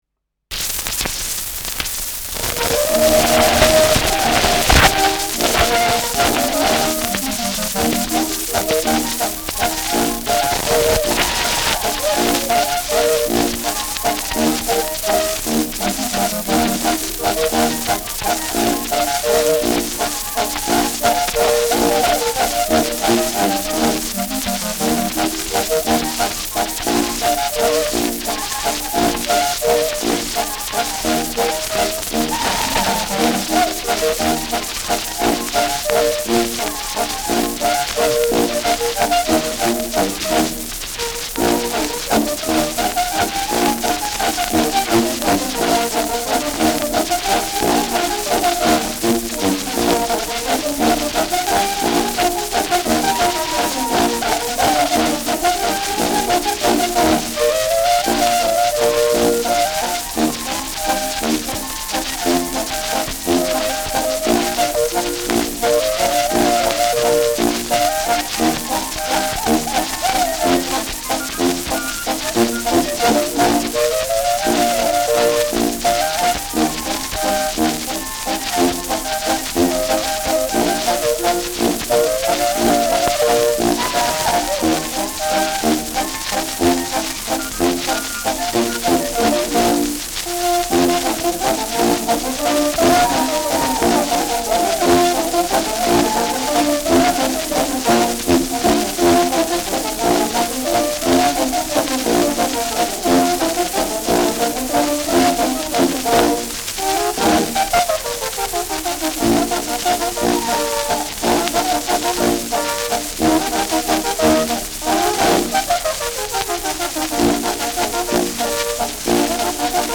Schellackplatte
starkes Rauschen : „Schnarren“ : abgespielt : leiert : präsentes Knistern : Nadelgeräusch
Kapelle Winkler, Nürnberg (Interpretation)
Dachauer Bauernkapelle (Interpretation)